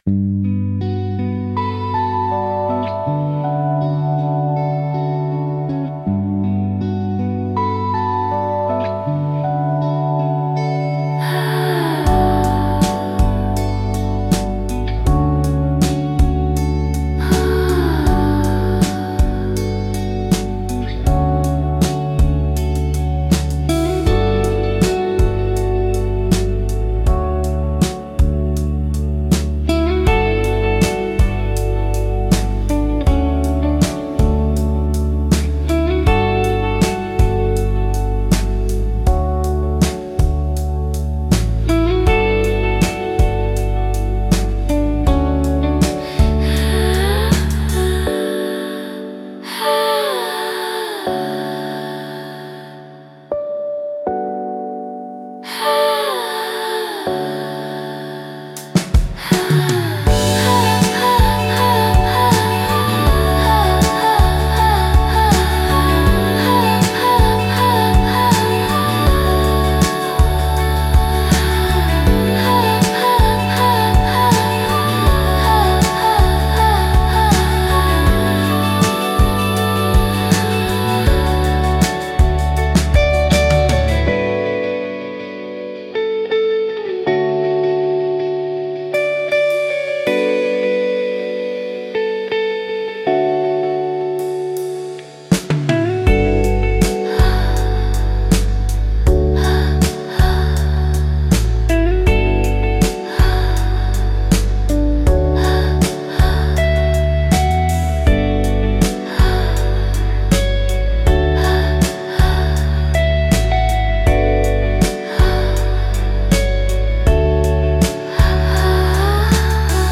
ドリームポップは、繊細で幻想的なサウンドが特徴のジャンルです。
静かで美しい音の重なりが心地よく、感性を刺激しながらも邪魔にならない背景音楽として活用されます。